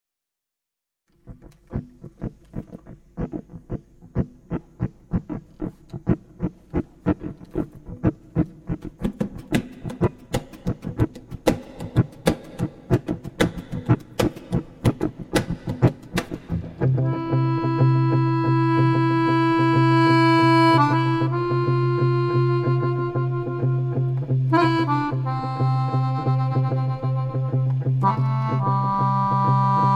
guitar
accordion